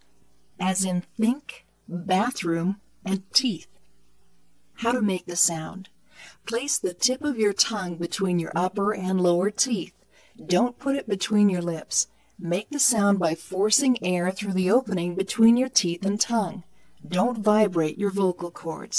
後で紹介するフリーソフトでWaveファイルに変換し、さらに別のフリーソフトで減速させました。
あくまでもフリーソフトなので音質はあまりよくありませんが、聞き取りには十分でしょう。